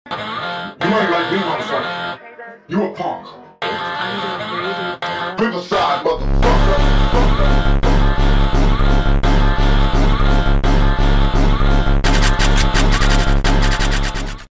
Jersey club